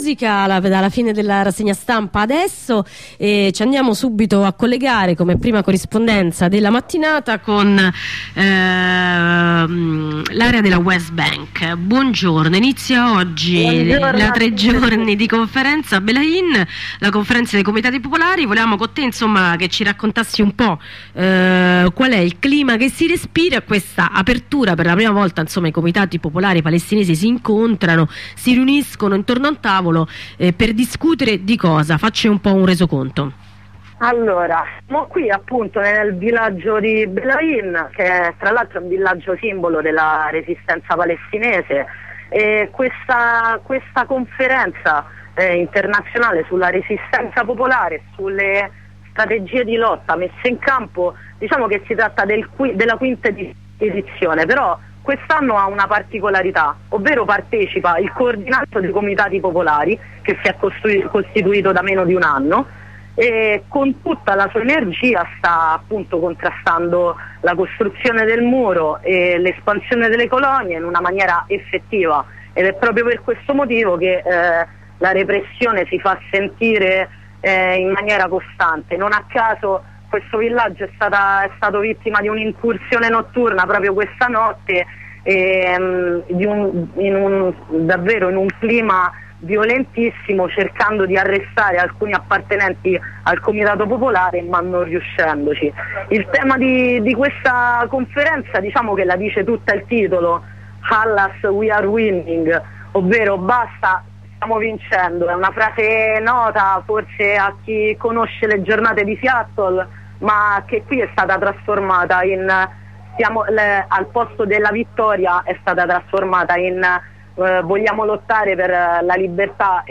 Ascolta una corrispondenza da Radiondarossa